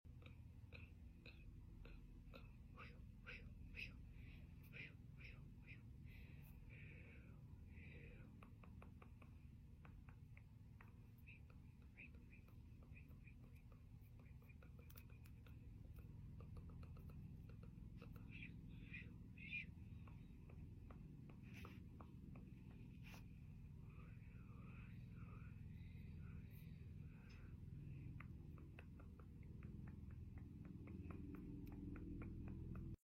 Hand Movements Are The Best Sound Effects Free Download